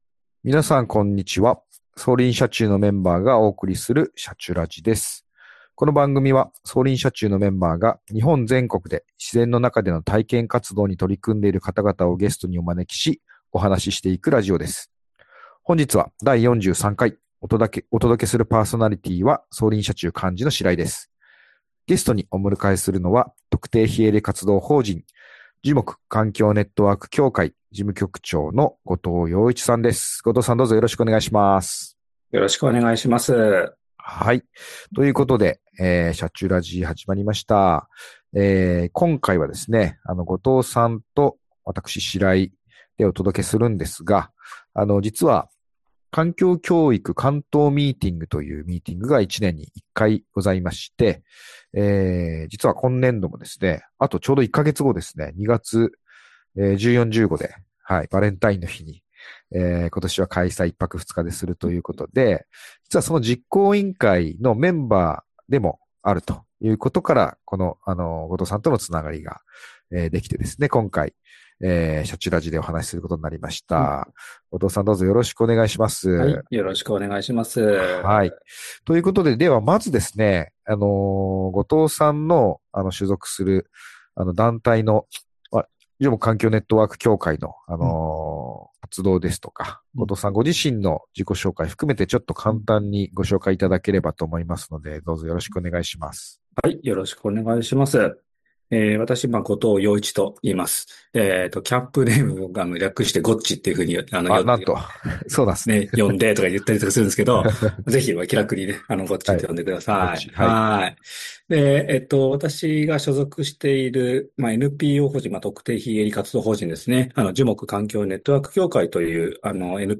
【今回のゲストスピーカー】